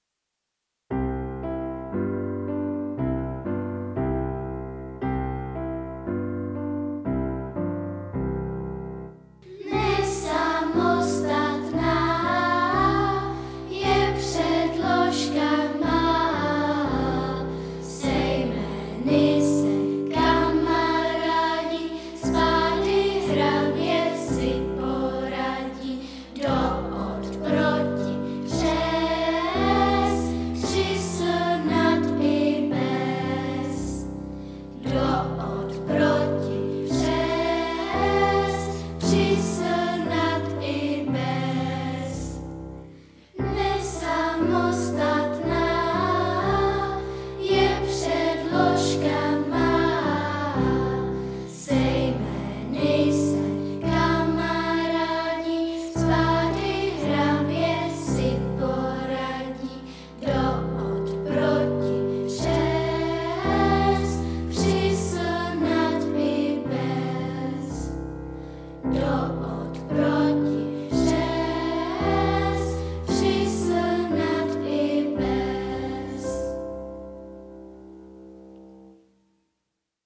predlozky-pisen.mp3